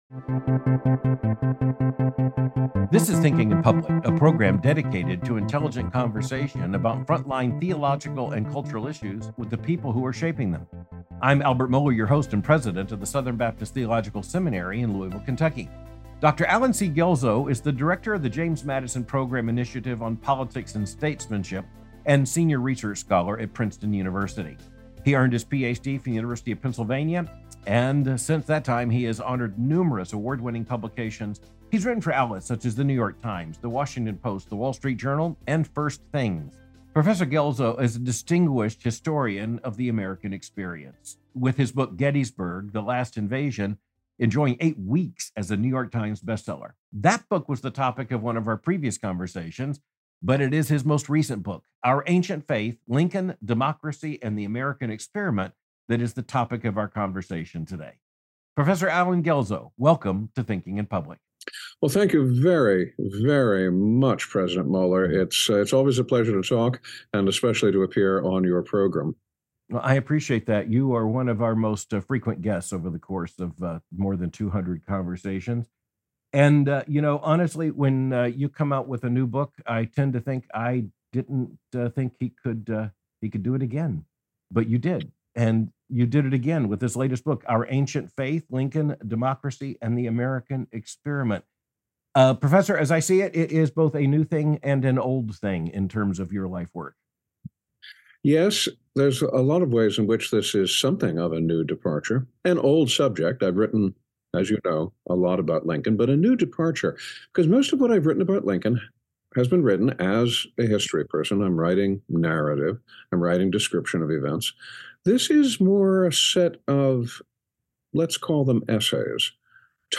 This is Thinking in Public, a program dedicated to intelligent conversation about frontline theological and cultural issues with the people who are shaping them.
Abraham Lincoln: God’s Providence, Natural Law, Liberal Democracy — A Conversation with Historian Allen Guelzo